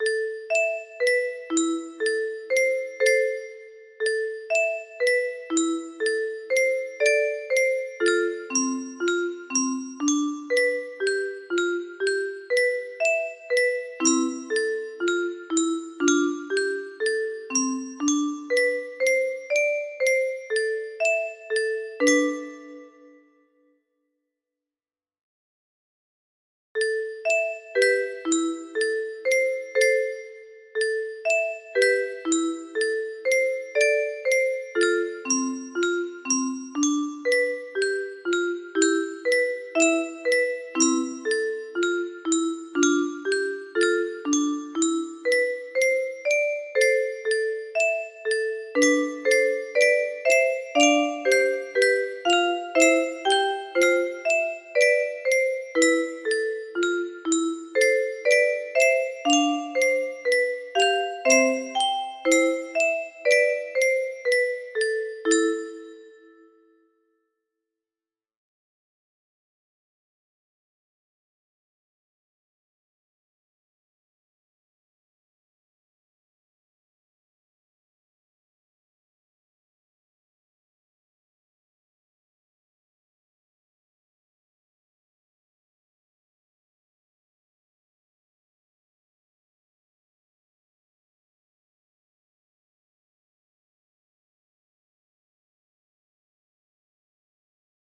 (2 octaves diatonic)